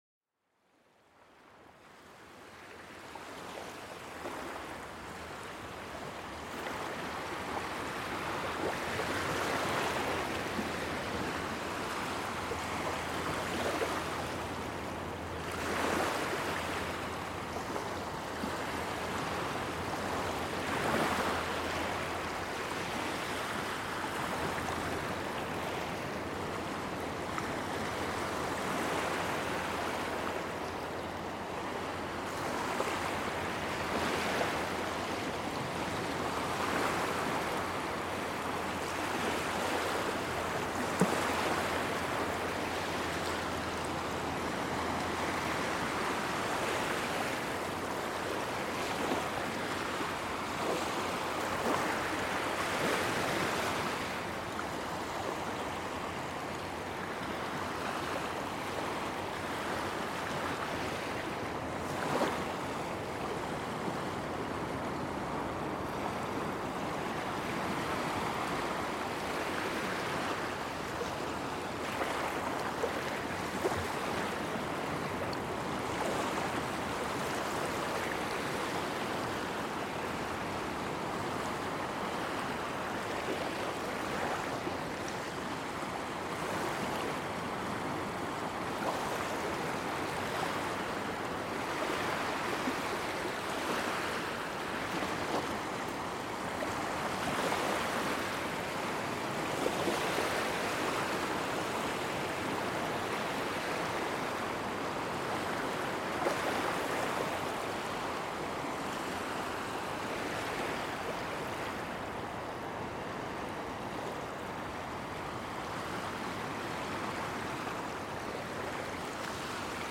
Olas del Mar: Relajación y Serenidad